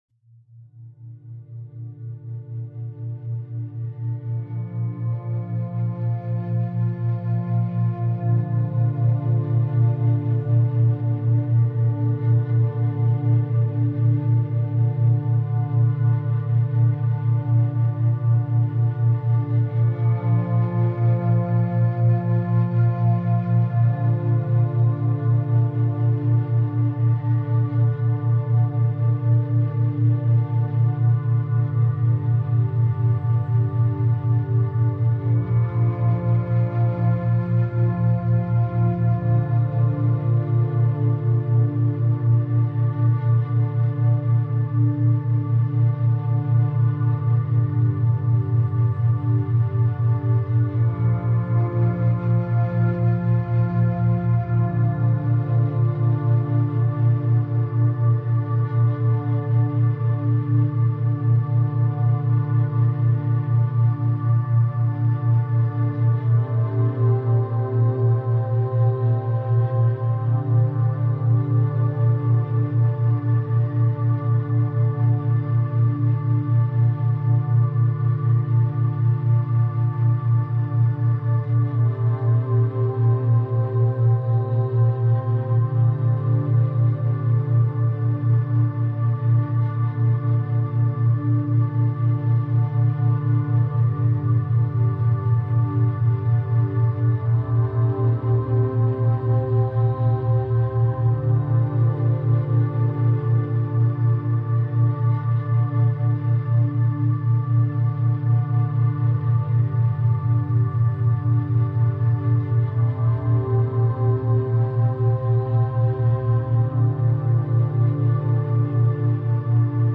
Feu brumeux naturel · méthode 50-10 essentielle pour étude chaleureuse